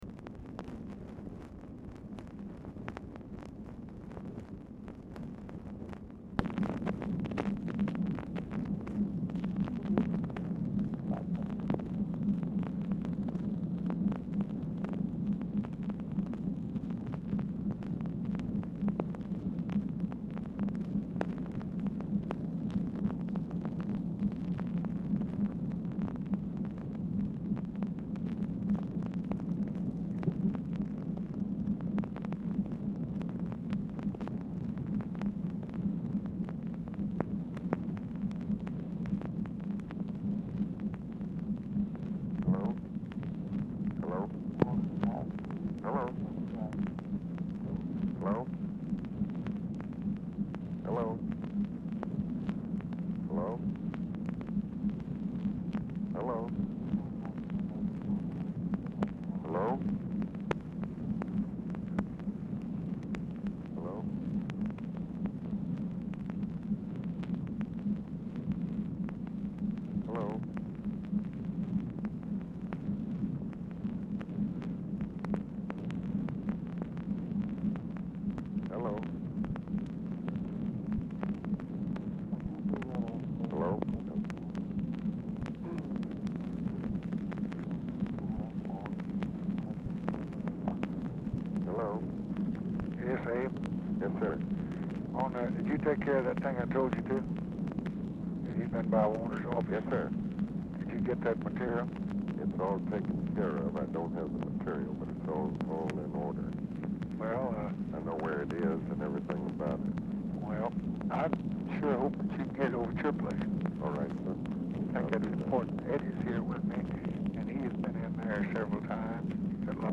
POOR SOUND QUALITY
0:40 MACHINE NOISE PRECEDES CALL
Format Dictation belt
Specific Item Type Telephone conversation